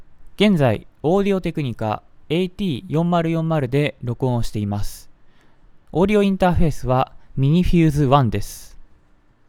Audio technica /AT4040(コンデンサーマイク)